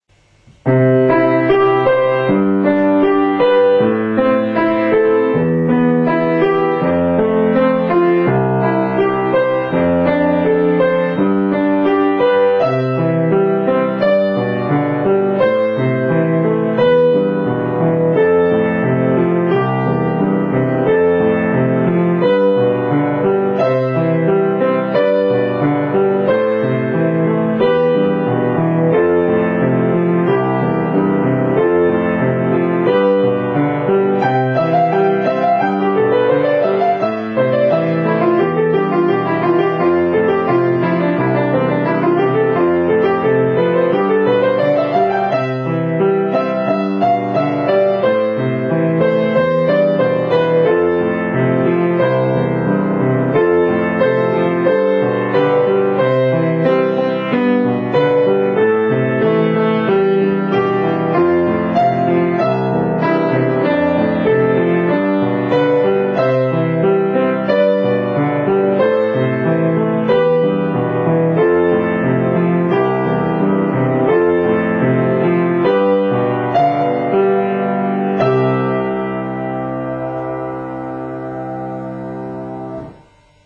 ピアノのしらべ：パッヘルベル作曲「カノン」
それでは、ハ長調に移調した「カノン」がどのような曲になるのか、聴いてみてください。